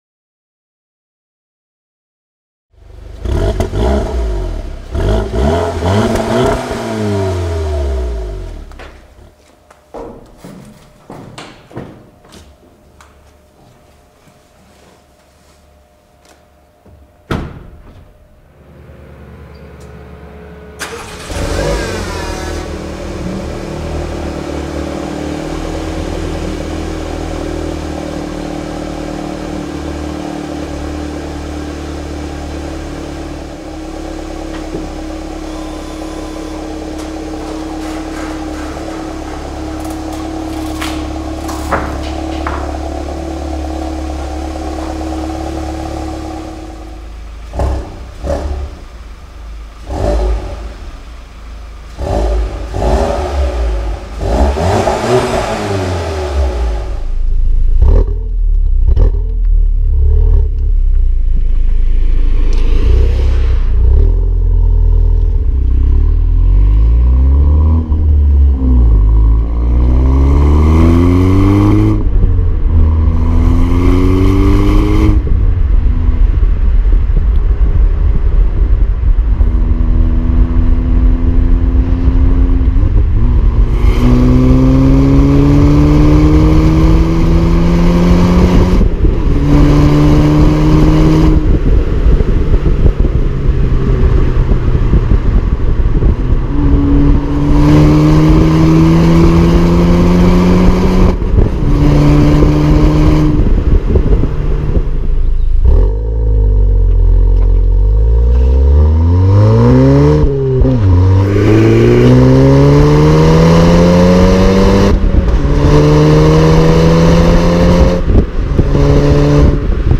ENGINE SIZE 2 L TFSI Quattro
Audi-TT-Coupe.mp3